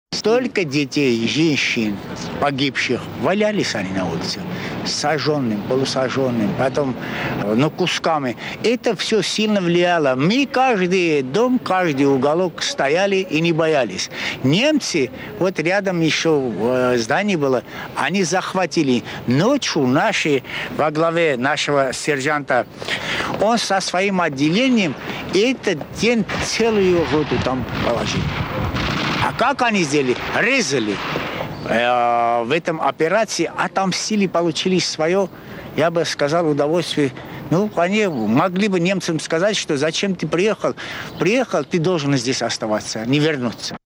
Участник Сталинградской битвы вспоминает о боях в городе
Архивная запись